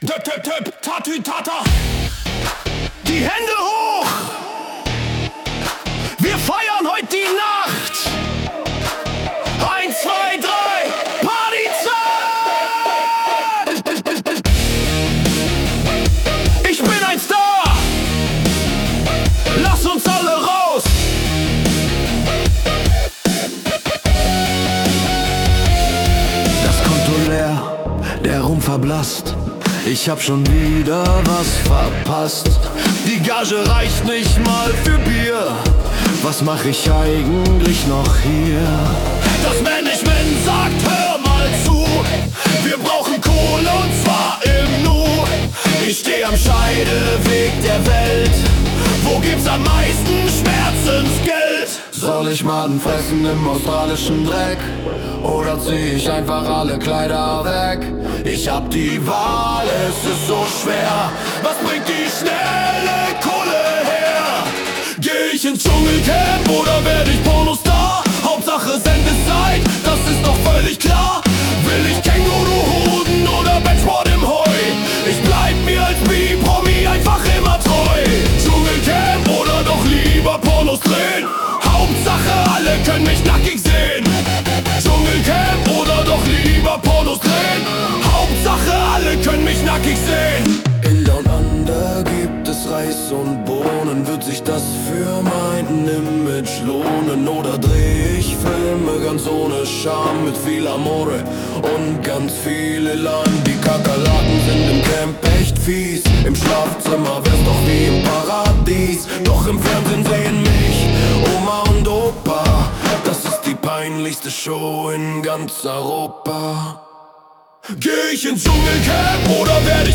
deutsch Rock Rap